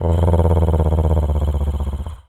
cat_purr_deep_02.wav